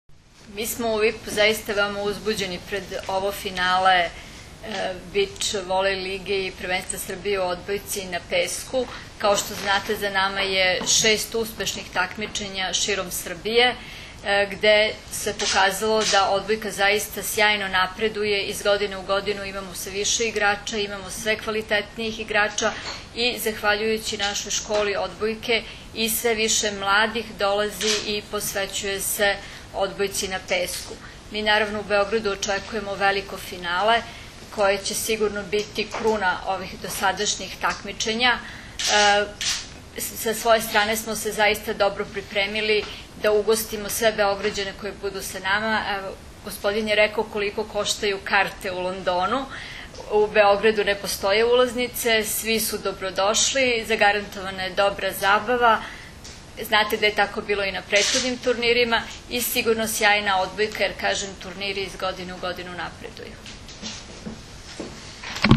U prostorijama Odbojkaškog saveza Srbije danas je održana konferencija za novinare povodom Finalnog turnira 5. „Vip Beach Masters 2012. – Prvenstva Srbije u odbojci na pesku“, koji će se odigrati narednog vikenda na Adi Ciganliji, u Beogradu.
IZJAVA